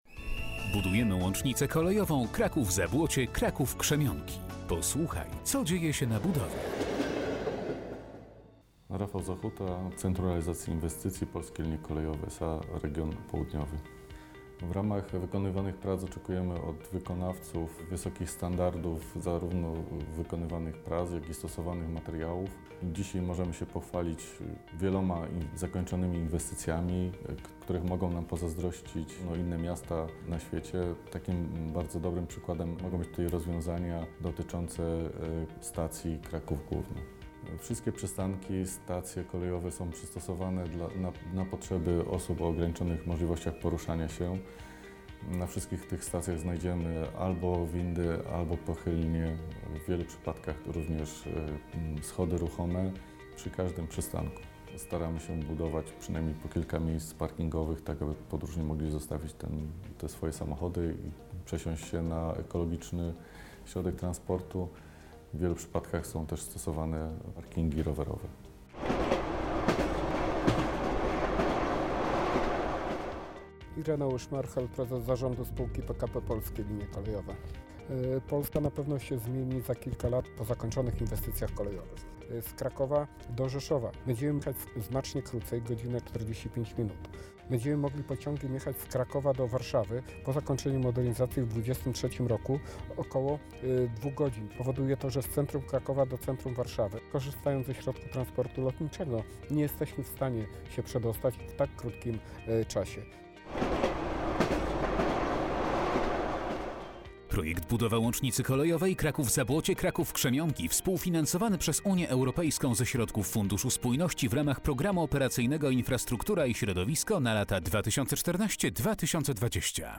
2017-07-21 cz. 2 audycja radiowa dot. budowy łącznicy Kraków Zabłocie - Kraków Krzemionki, emisja: lipiec 2017 r.